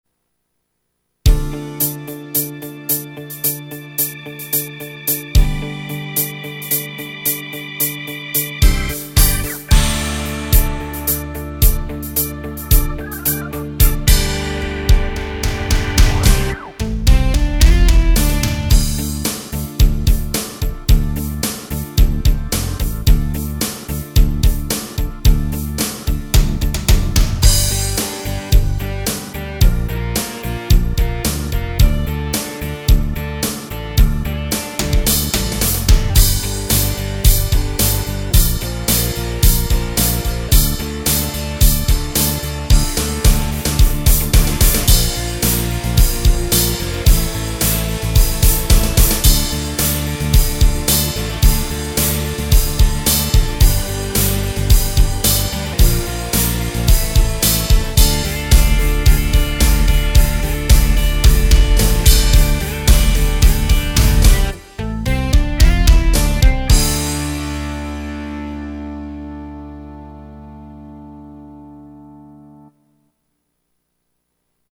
tu-hard rock.mp3